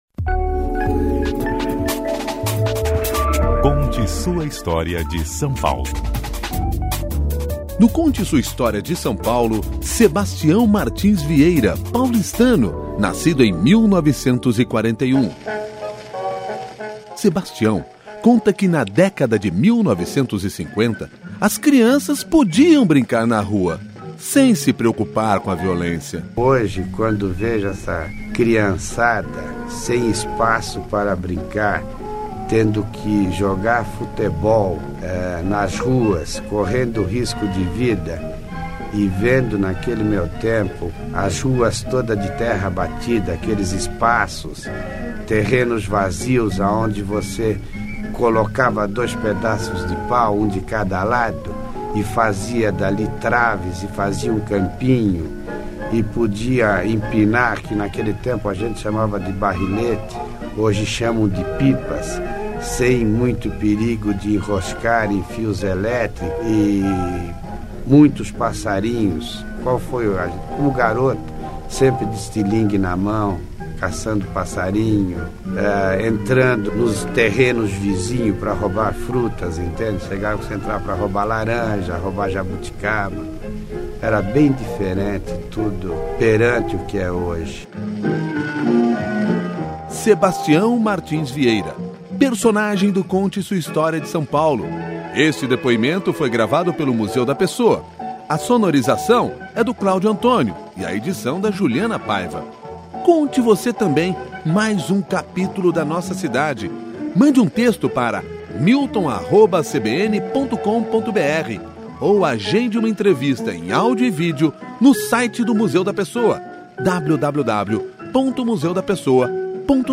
O Conte Sua História de São Paulo vai ao ar aos sábados, a partir das 10 e meia da manhã, no CBN São Paulo.